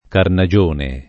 [ karna J1 ne ]